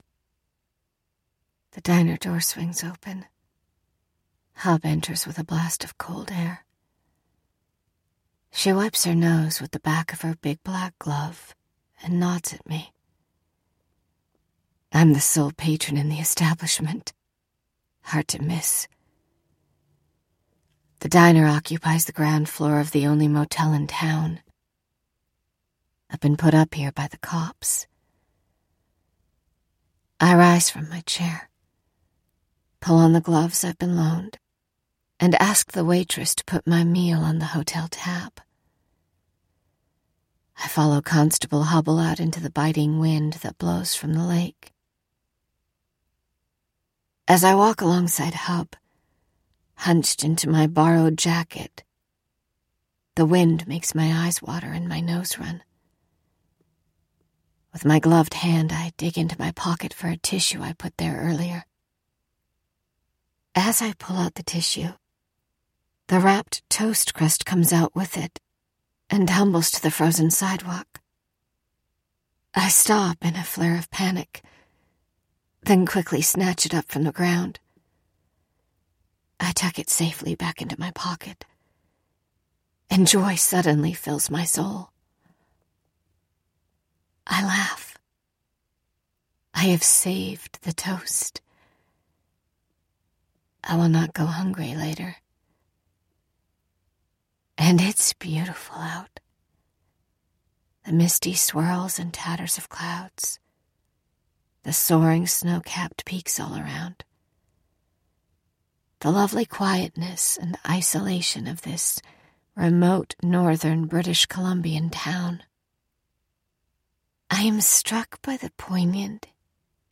AUDIOBOOK  SAMPLES
Mystery / Suspense  |    First Person    |   Narrative